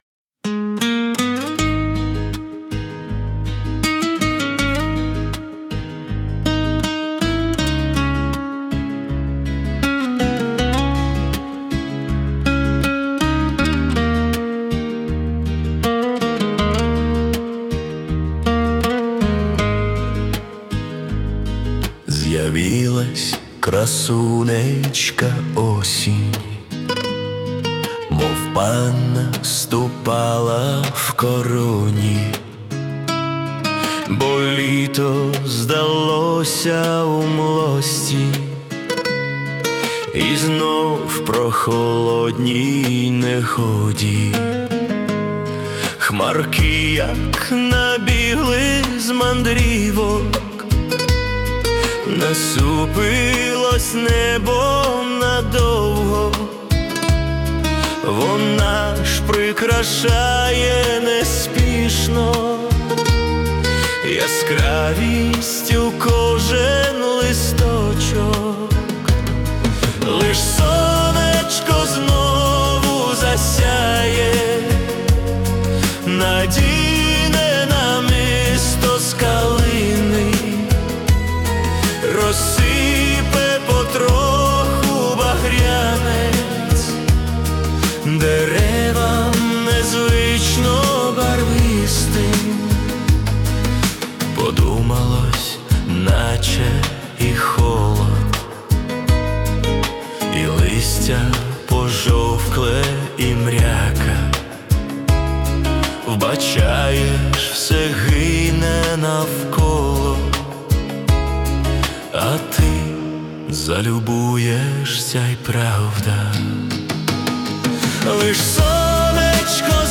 Музична композиція створена за допомогою SUNO AI
СТИЛЬОВІ ЖАНРИ: Ліричний
Ніжно, тендітно.